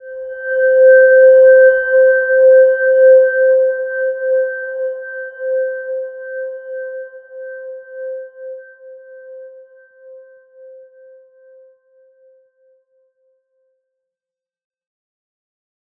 Silver-Gem-C5-p.wav